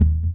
piston.Kick_14.wav